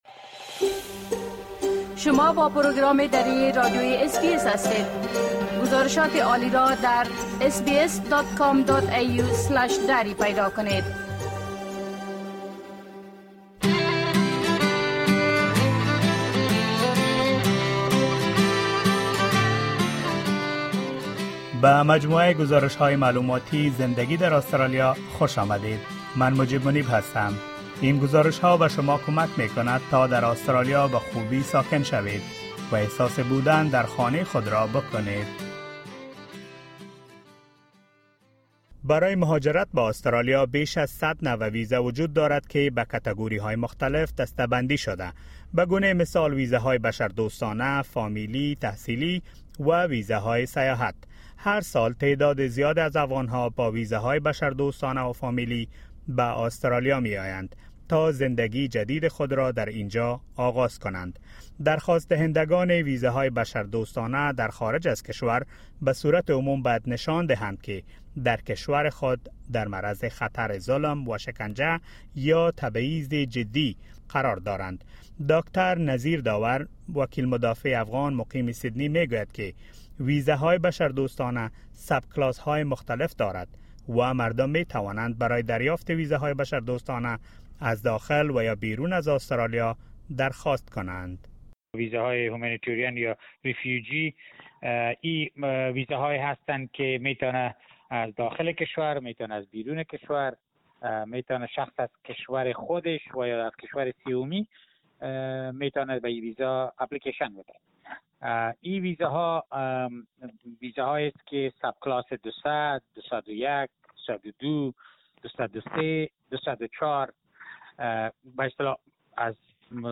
There are more than 100 types of Australian visas which are categorised in different groups, such as visitor, family, study, work and humanitarian visas. In this informative report, we have gathered information about immigration and visas for Australia.